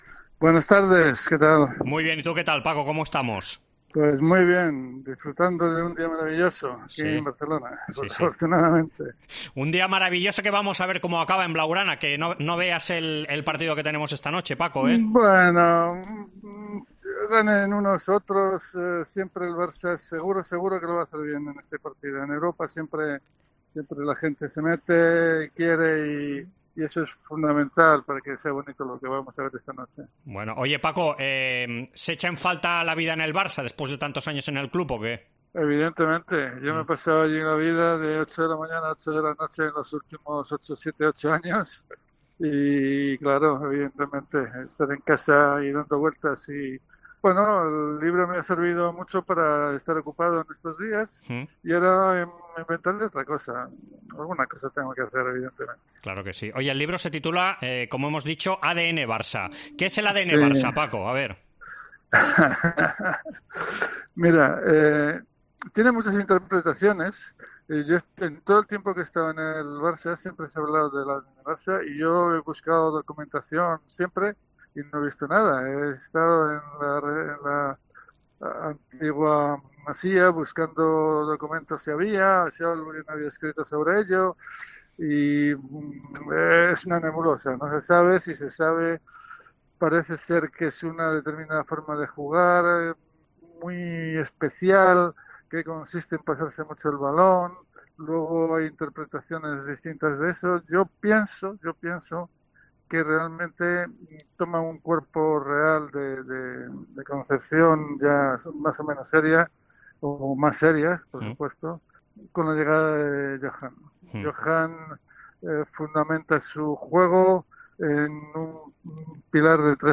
Entrevista Esports COPE